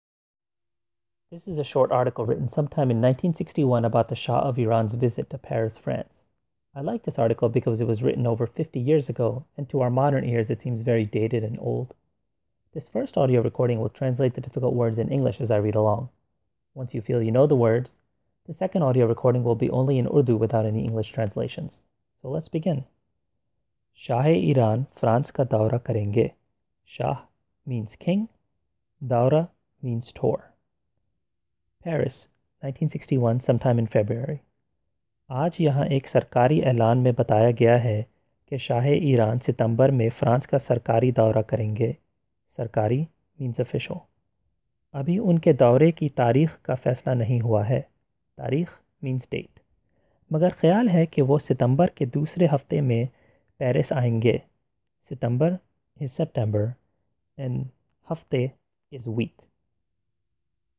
The first audio recording will translate the difficult words in English as I read along in Urdu.